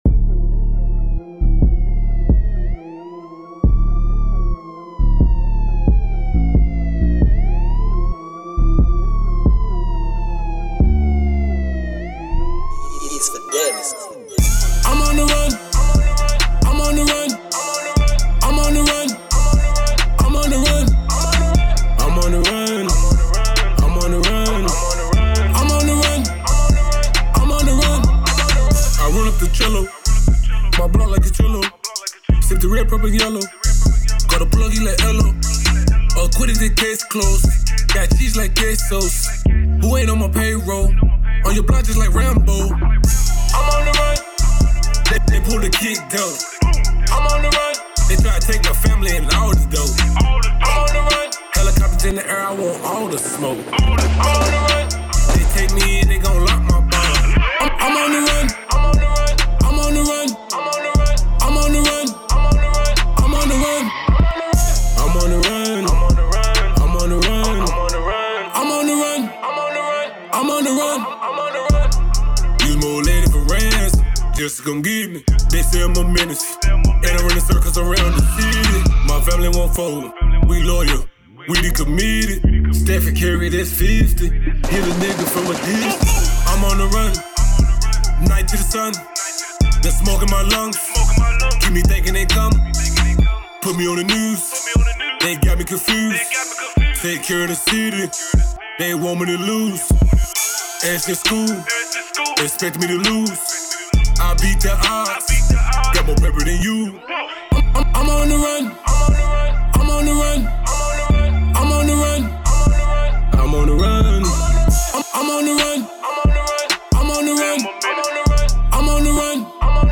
Hiphop
Street Banger